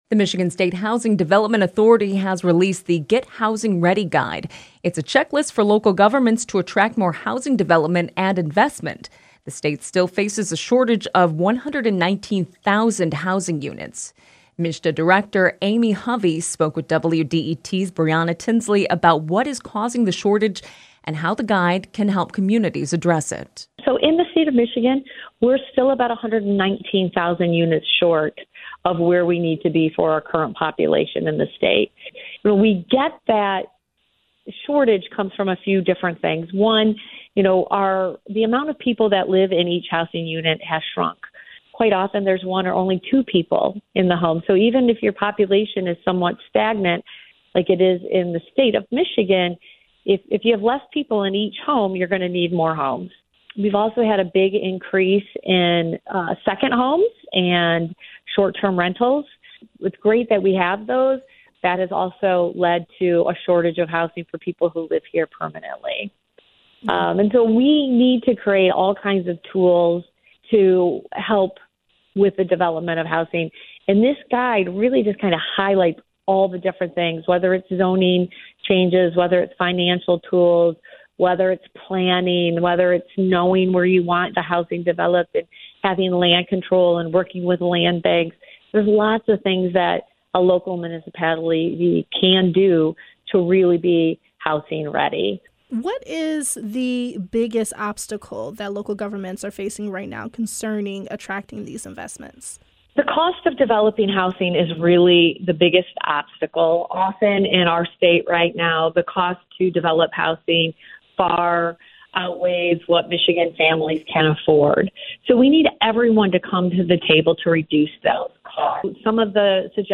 MSHDA Director Amy Hovey spoke with WDET about what is causing the shortage, and how the guide can help communities address it.
The following interview has been edited for clarity and length.